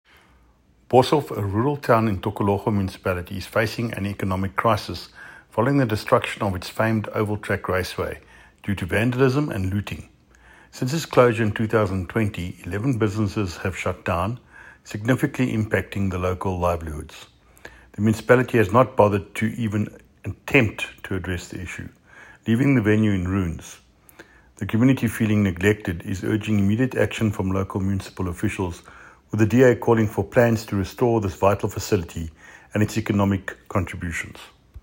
Afrikaans soundbites by David Mc Kay MPL and Sesotho soundbite by Jafta Mokoena MPL and images here,here,here,here,here and here